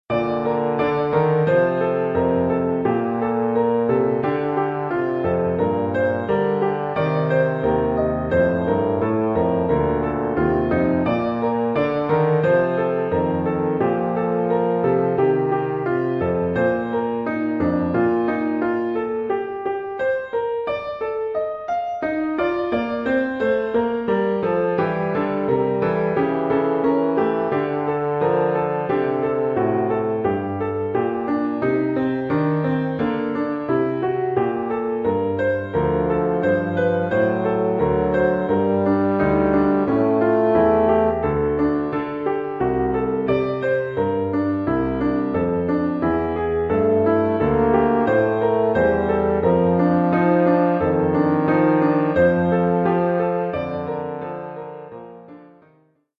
Oeuvre pour saxhorn basse /
euphonium / tuba et piano.